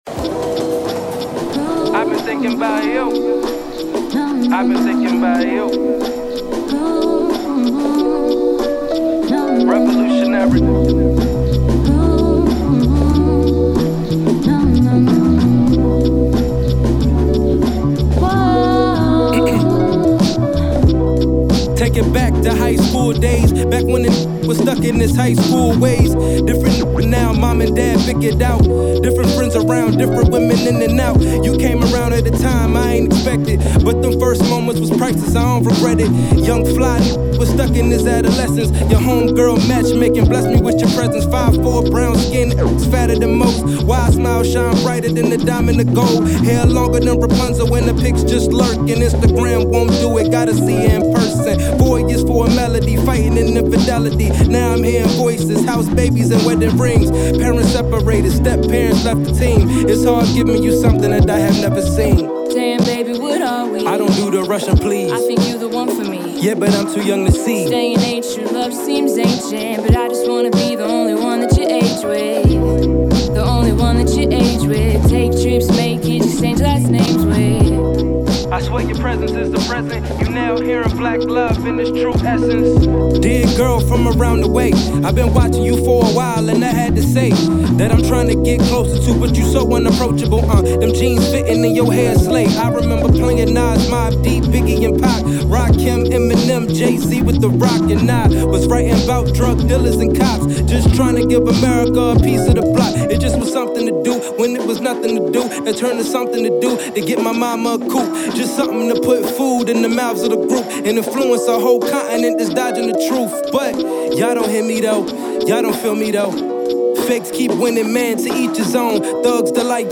SINGLESHIP-HOP/RAP